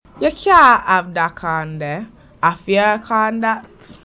Examples of Jamaican English
//ju kjaː hæv da kaːn deː/a fɪə kaːn dat//
Notice the pronunciation of cyan = can, which is characteristic of this creole. The /j/ is inserted after /k/ and /g/ and before the vowel /a/.